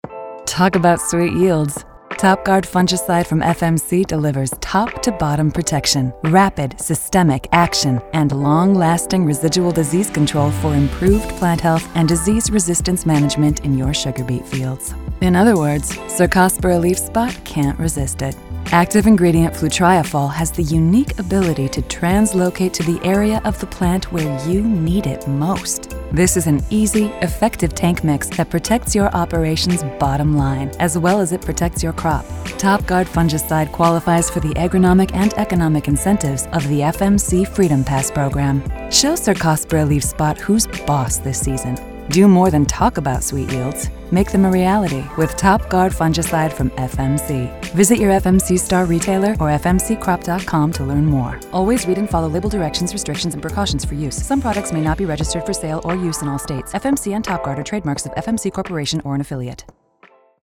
Four produced radio spots supporting print and digital campaigns.
topguard-sugar-beet-radio-60.mp3